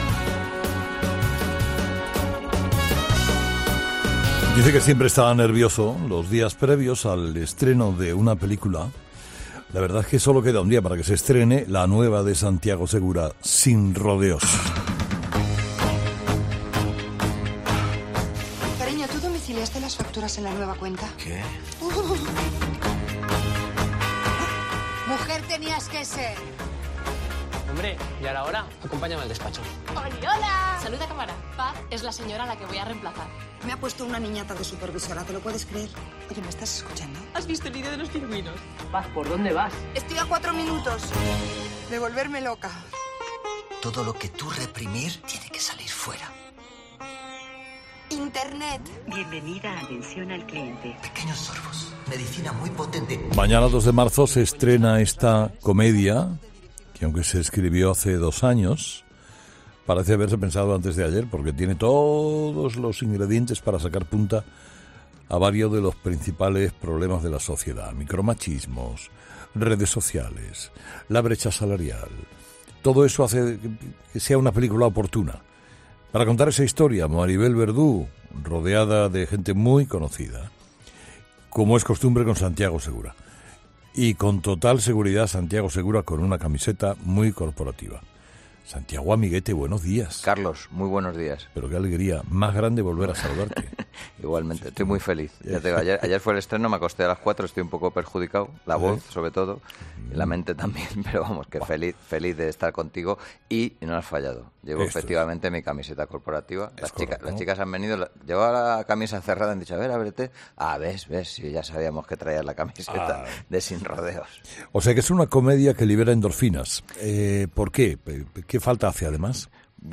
Santiago Segura presenta "Sin rodeos" en Herrera en COPE